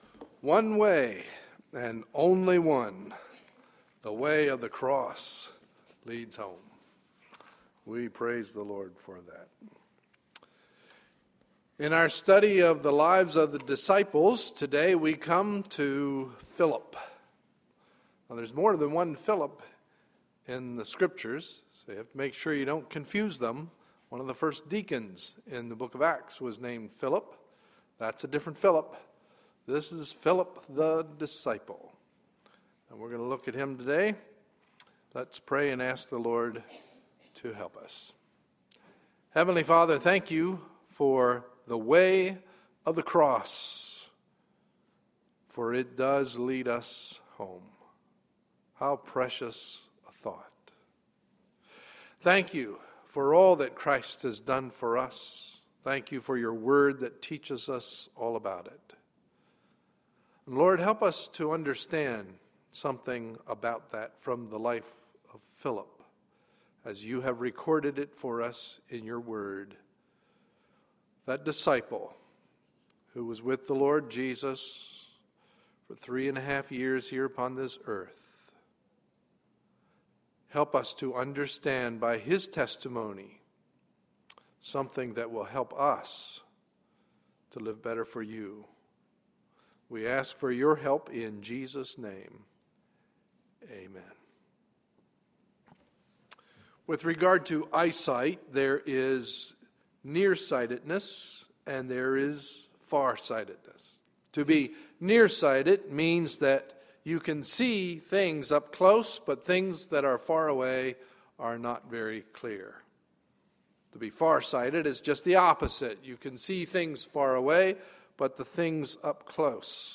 Sunday, July 1, 2012 – Morning Message